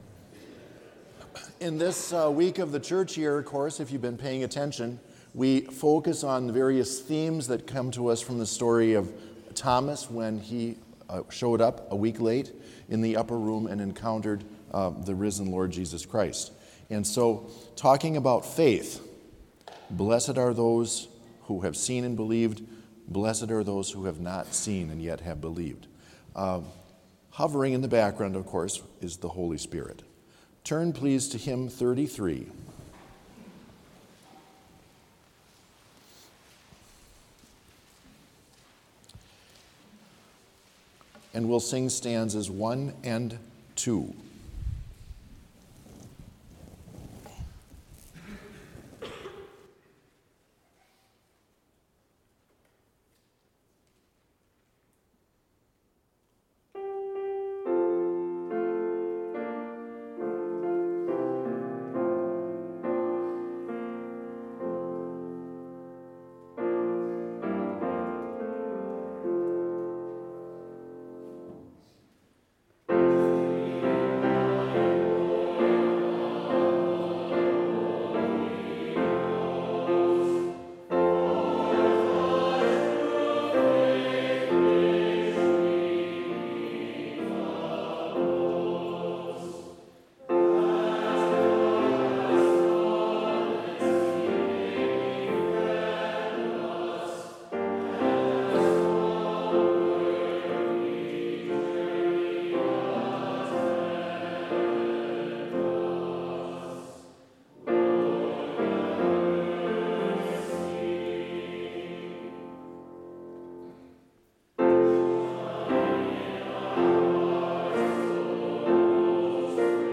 Complete service audio for Chapel - April 20, 2023